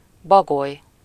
Ääntäminen
Synonyymit fiancée Ääntäminen France: IPA: [œ̃ i.bu] France (Île-de-France): IPA: /i.bu/ Paris: IPA: [i.bu] Haettu sana löytyi näillä lähdekielillä: ranska Käännös Konteksti Ääninäyte Substantiivit 1. bagoly lintu, eläintiede Suku: m .